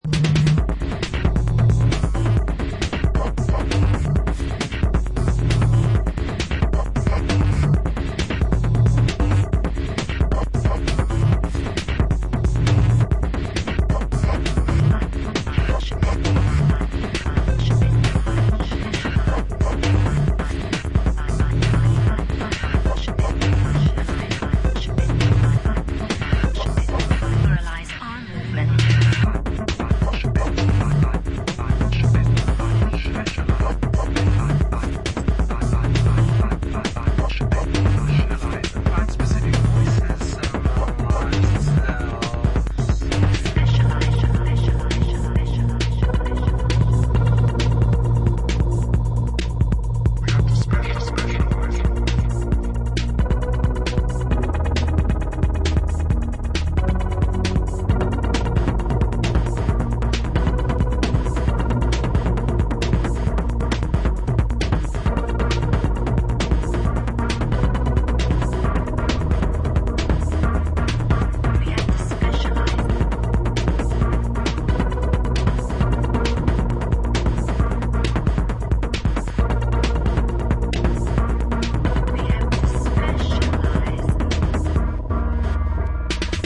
supplier of essential dance music
Electro Electronix Techno